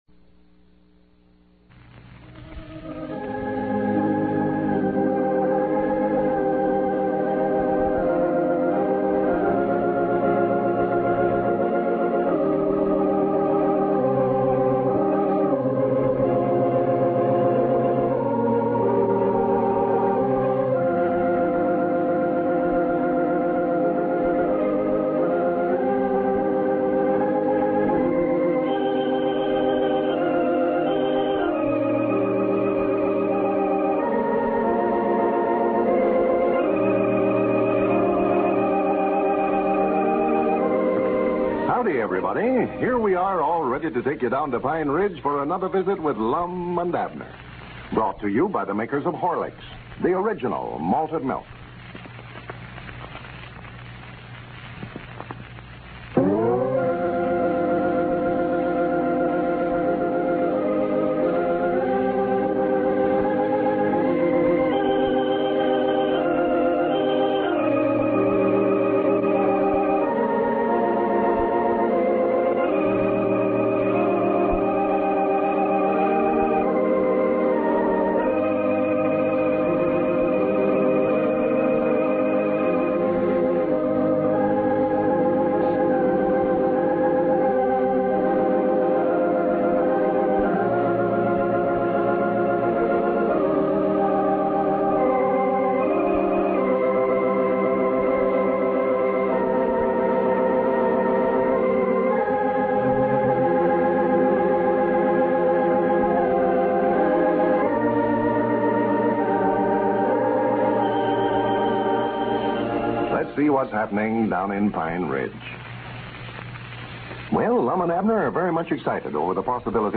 Lum and Abner! A classic radio show that brought laughter to millions of Americans from 1931 to 1954.